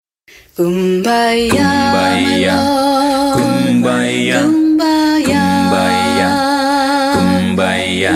Las tres primeras notas de esta conocida canción forman un acorde tríada.